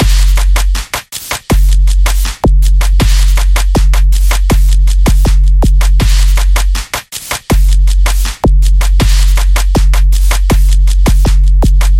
Hiphop Big Kick Beat 80bpm A 2
描述：Another version with more percs and less noises
标签： 80 bpm Hip Hop Loops Drum Loops 2.02 MB wav Key : A
声道立体声